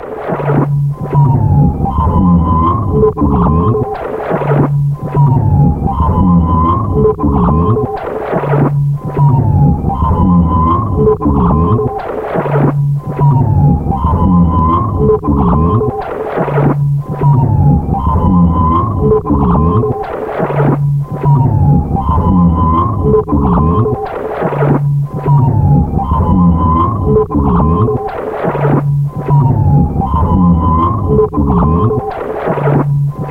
卷到卷的磁带操纵/噪音循环 " Hum Ransom
描述：扭曲的音调下降的声音和磁带操纵，听起来像警报器，记录在1/4"磁带上，被切割和物理循环
Tag: 警报器 失真 音调下降 切割时 带环 胶带操纵 语音